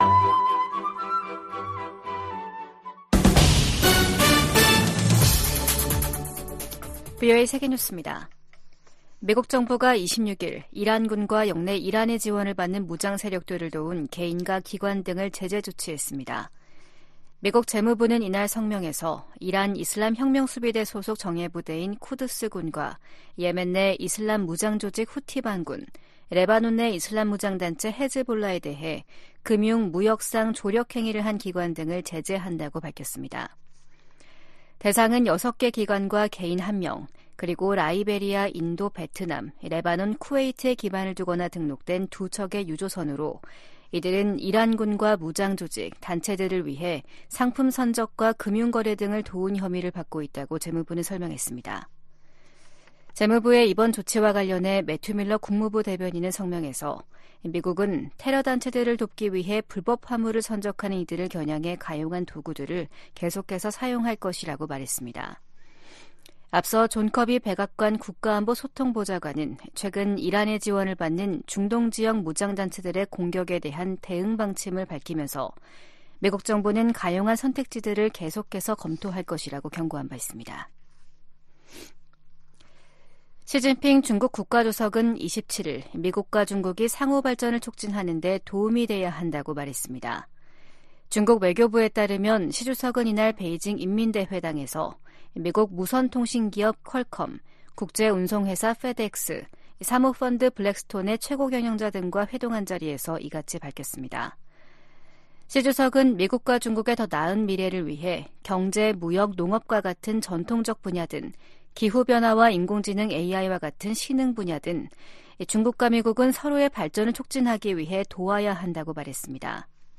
VOA 한국어 아침 뉴스 프로그램 '워싱턴 뉴스 광장'입니다. 북한의 김여정 노동당 부부장이 일본과의 정상회담과 관련, 어떤 교섭이나 접촉도 거부할 것이라고 밝혔습니다. 미국 정부가 북한-러시아 군사 협력에 우려를 거듭 표명하고, 북한 무기가 무고한 우크라이나인 살해에 사용되고 있다고 지적했습니다. 기시다 후미오 일본 총리의 다음달 국빈 방미를 계기로 미일 군사 안보 동맹이 업그레이드 될 것으로 전문가들은 전망하고 있습니다.